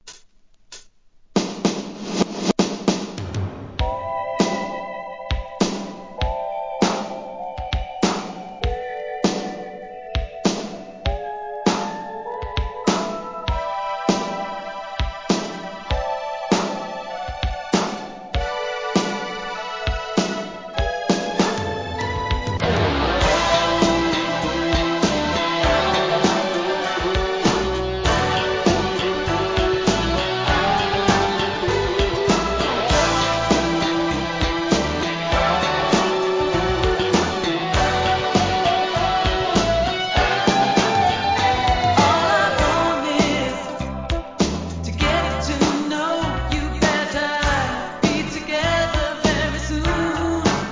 ¥ 1,100 税込 関連カテゴリ SOUL/FUNK/etc...